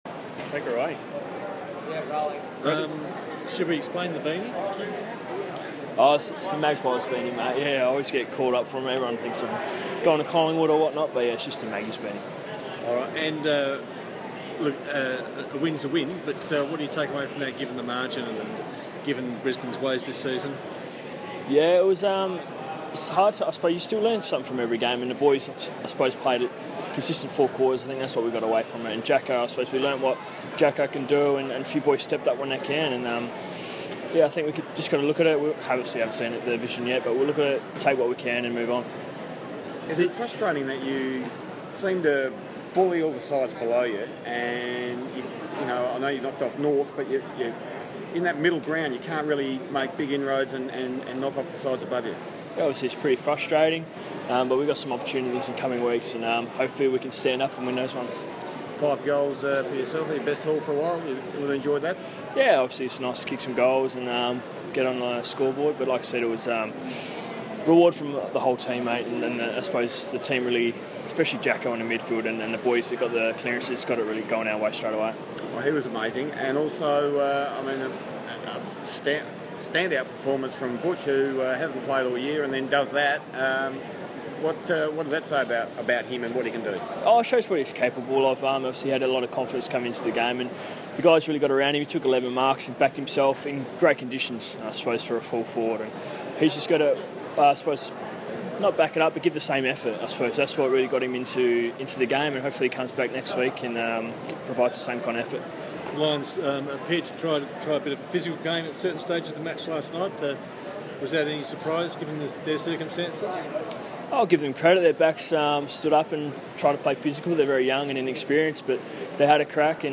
Chad Wingard press conference - Sunday, 31 July, 2016
Chad Wingard talks with media after Port Adelaide returns home from beating Brisbane by 94 points at the Gabba.